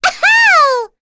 Rehartna-Vox_Happy9.wav